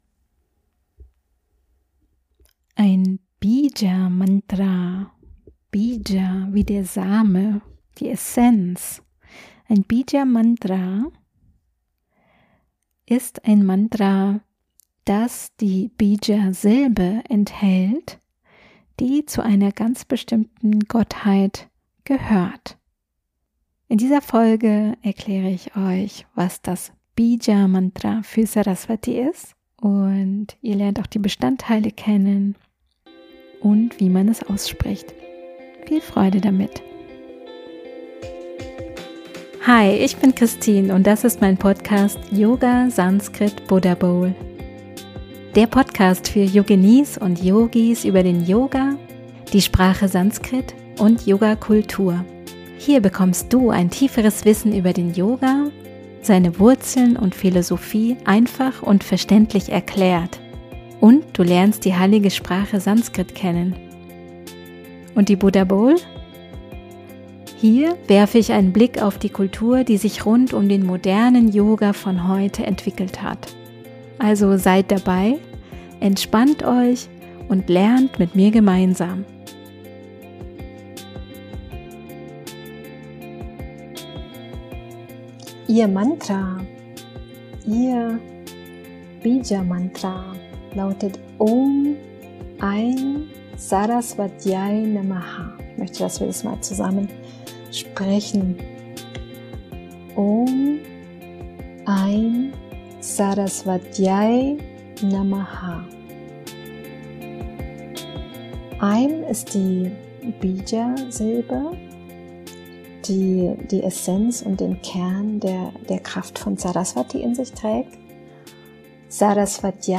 Ihr hört hier einen kleinen Auszug aus meinem Sanskrit Unterricht im YOGA WISDOM & PRACTICE PROGRAMM (Online).